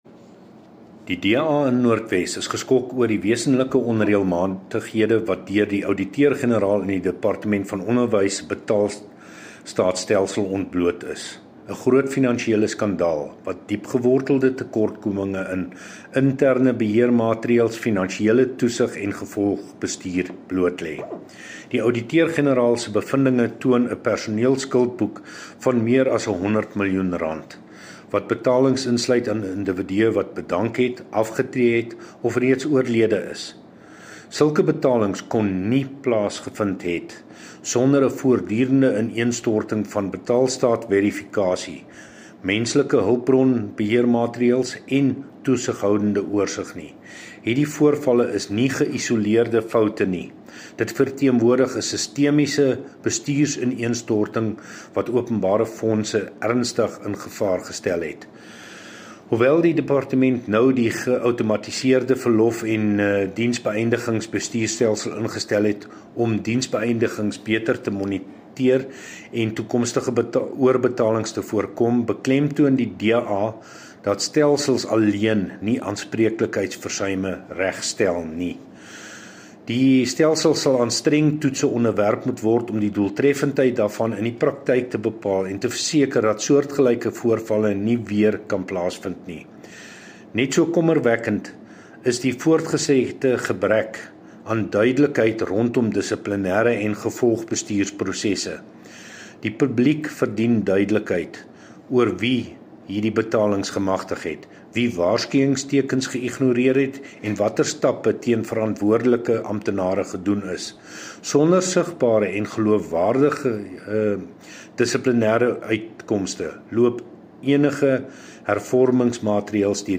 Note to Broadcasters: Please find the attached soundbites in